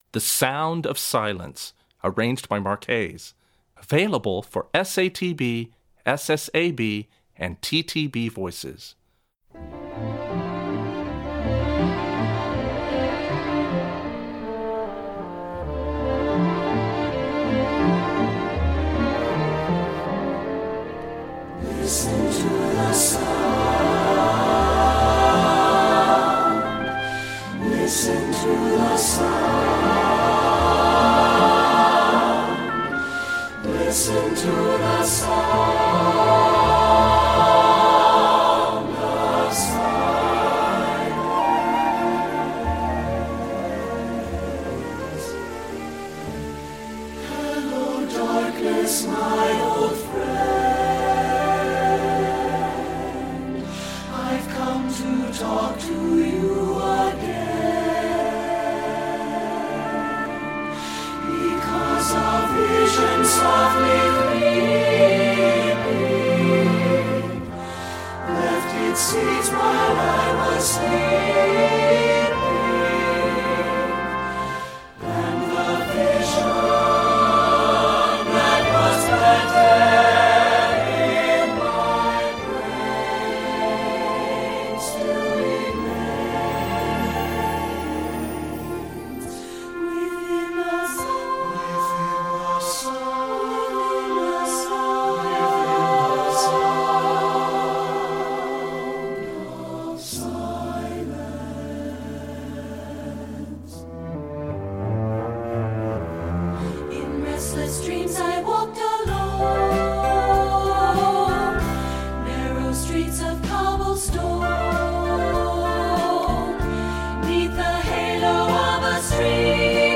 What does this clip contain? Voicing: SSAB